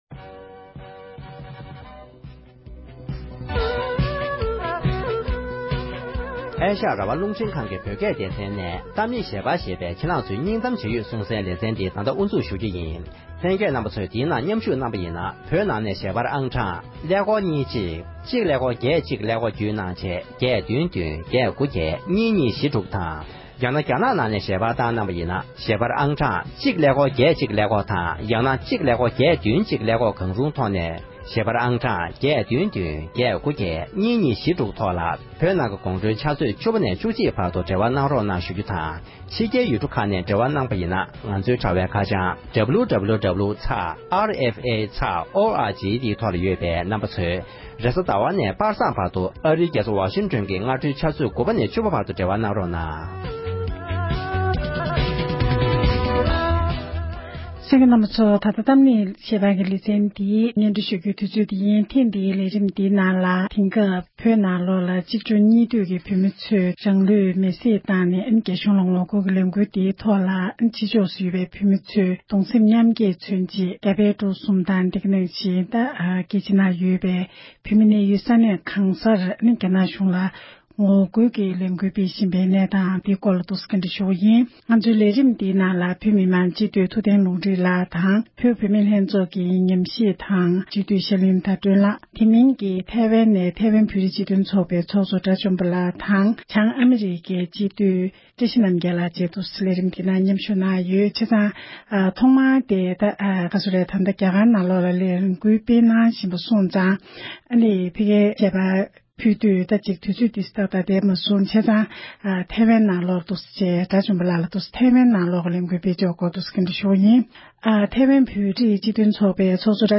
འབྲེལ་ཡོད་ལས་འགུལ་སྣེ་ཁྲིད་གནང་མཁན་རྣམས་དང་ལྷན་དུ་གླེང་མོལ་ཞུས་པ་ཞིག་ལ་གསན་རོགས་ཞུ༎